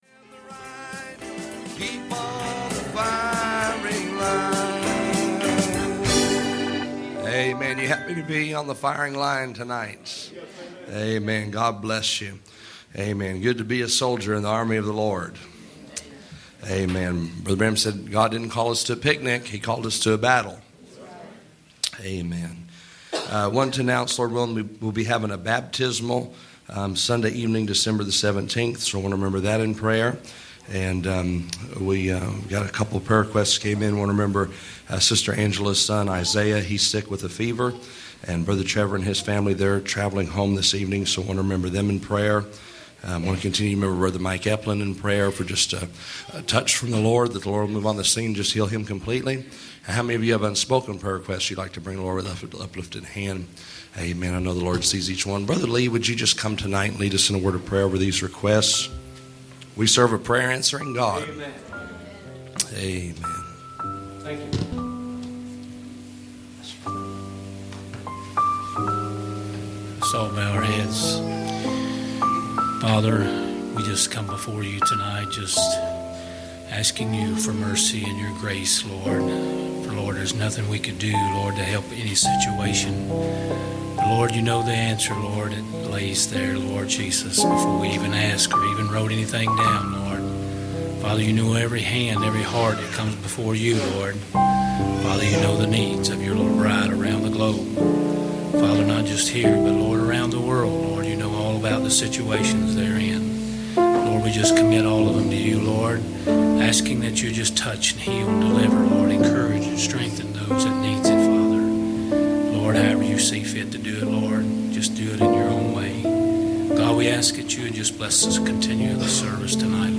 Passage: Hebrews 12:23 Service Type: Sunday Evening